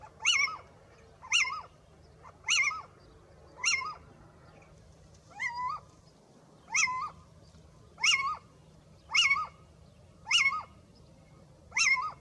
racconto di un’escursione nel Bosco delle Pianelle
Ci si congeda, con quello stato d’animo, che caratterizza chi ha vissuto a contatto con la natura, nel bosco gli animali notturni danno inizio alla loro giornata, la civetta, simbolo di questo luogo, emette il suo caratteristico
civetta.mp3